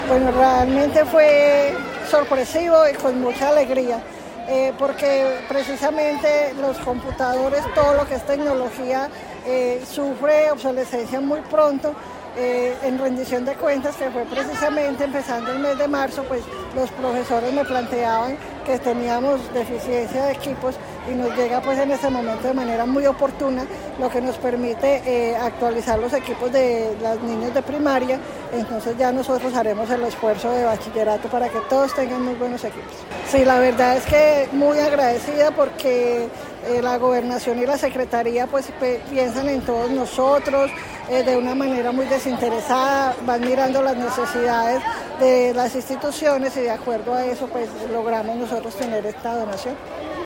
El acto de entrega se realizó en compañía de la comunidad de la Institución Educativa San Francisco de Paula.